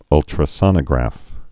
(ŭltrə-sŏnə-grăf, -sōnə-)